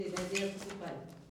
Langue Maraîchin